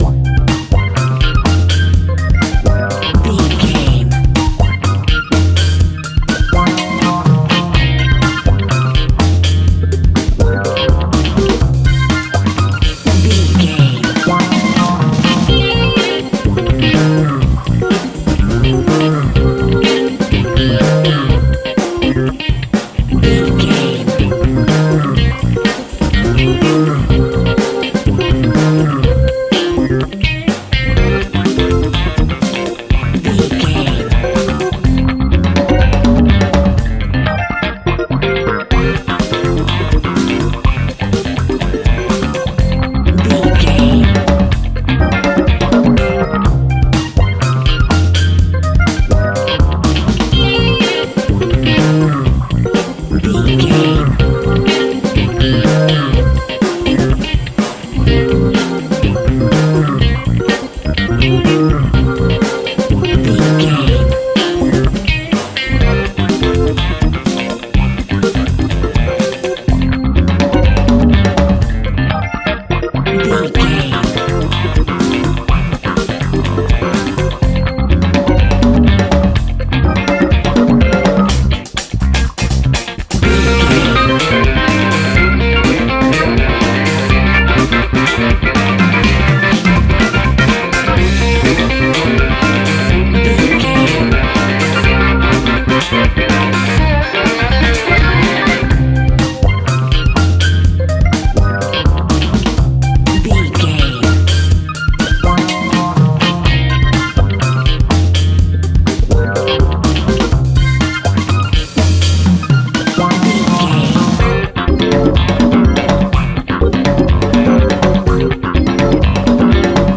Aeolian/Minor
groovy
lively
electric guitar
electric organ
drums
bass guitar
saxophone